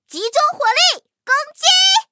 M4谢尔曼开火语音1.OGG